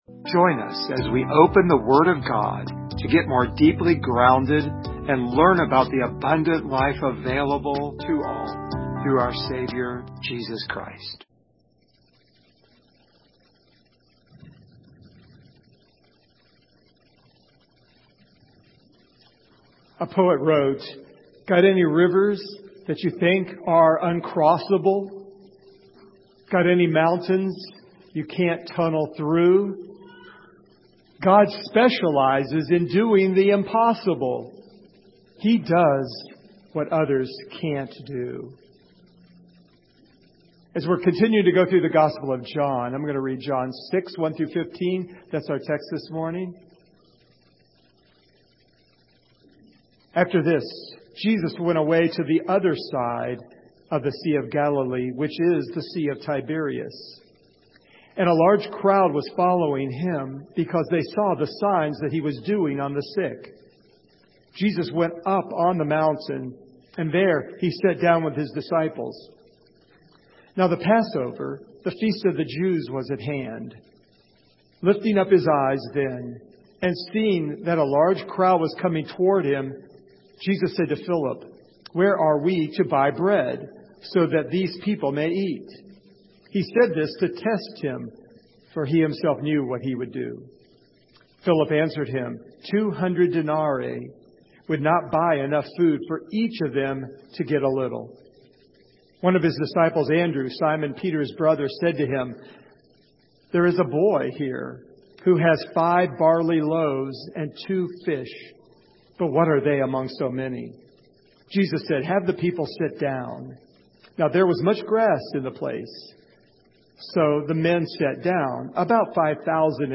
Service Type: Sunday Morning
Download Files Notes Topics: Miracles , Sanctification share this sermon « Jesus Is The Son Of God What Are You Afraid Of?